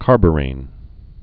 (kärbə-rān)